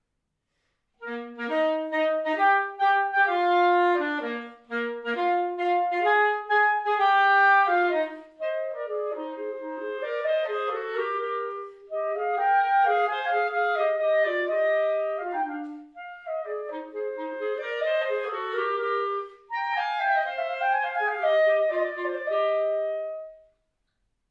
3 Sätze: Allegro molto, Romance, Finale Allegro assai
Besetzung: 2 Klarinetten